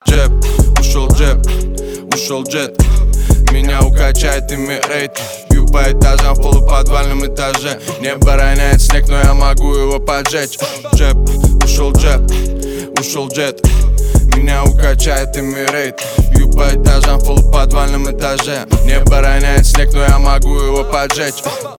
блатные , рэп